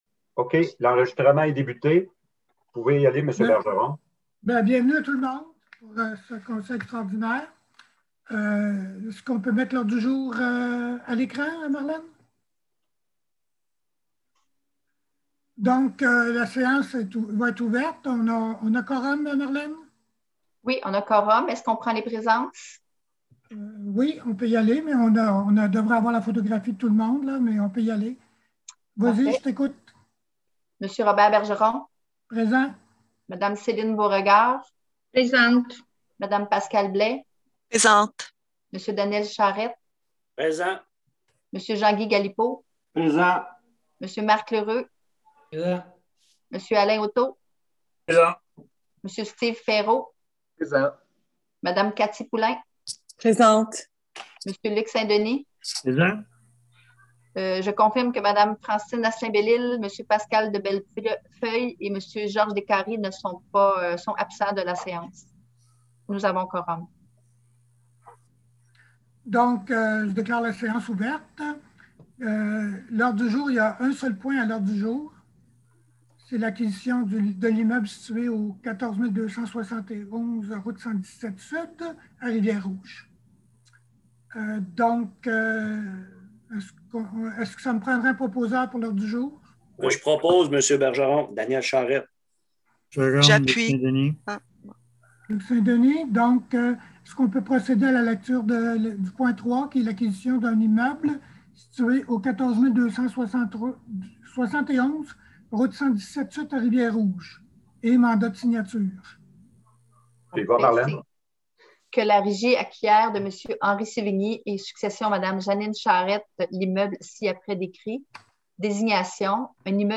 Procès-verbal du 8 avril 2021 – Séance extraordinaire  (pdf 108 Ko) Audio de la séance :